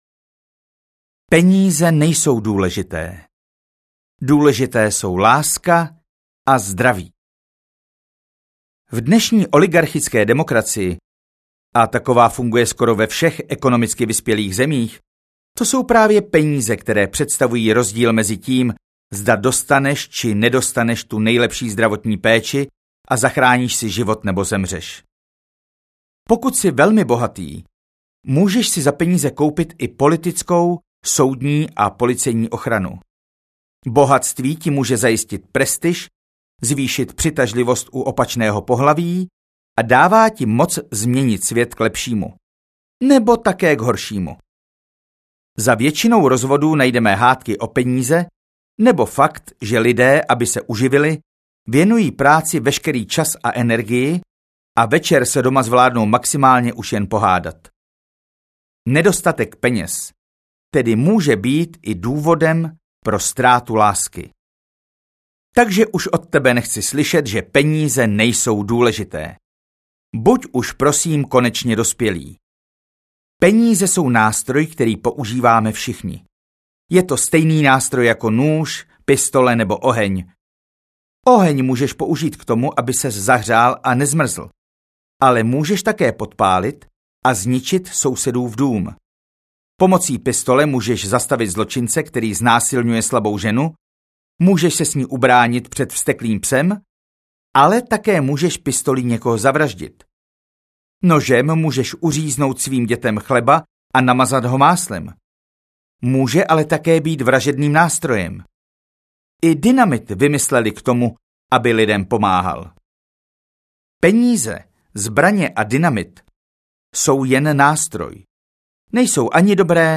Jak rychle zbohatnout audiokniha
Ukázka z knihy
jak-rychle-zbohatnout-audiokniha